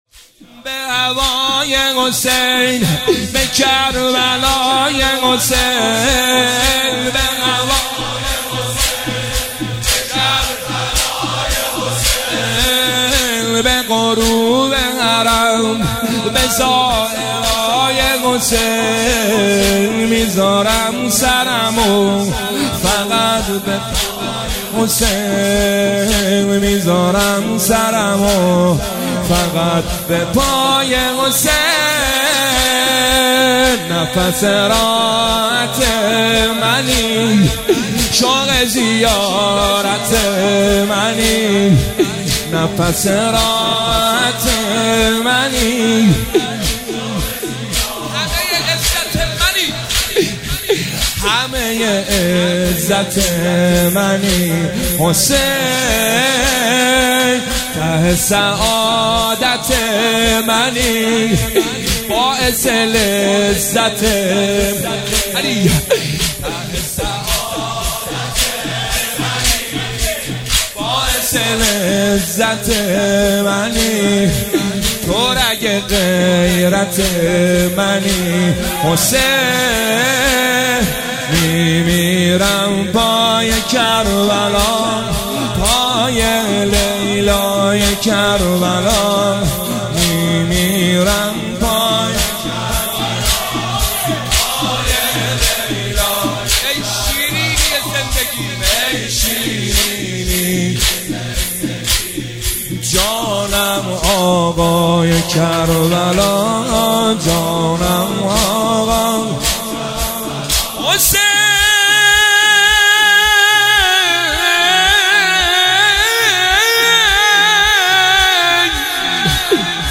هیئت هفتگی 27 اردیبهشت 1404